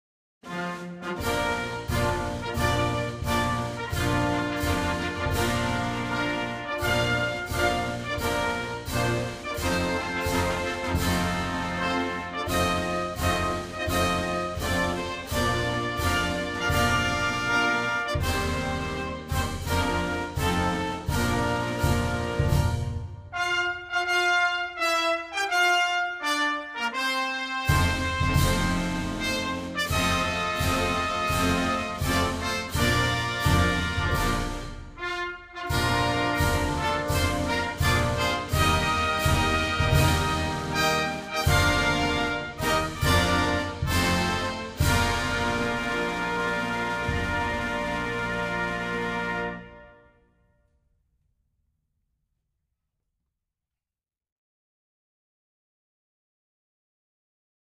O_Arise,_All_You_Sons_(instrumental).mp3